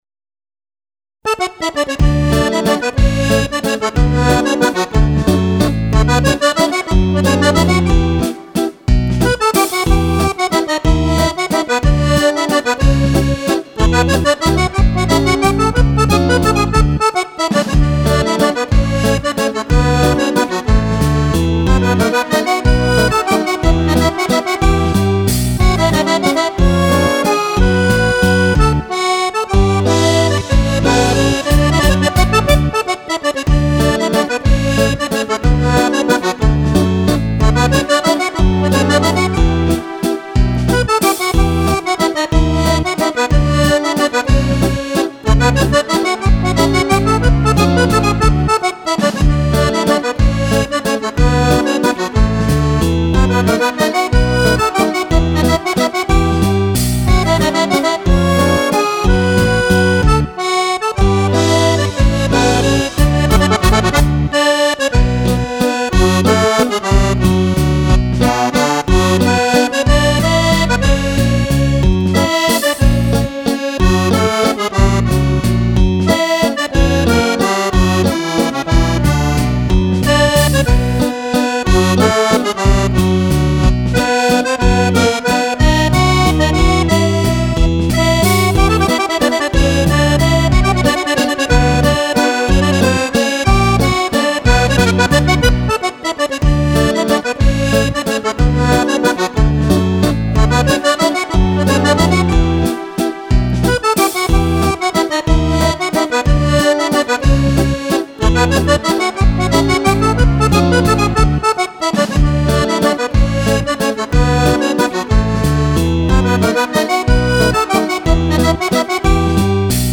Valzer musette
Valzer Musette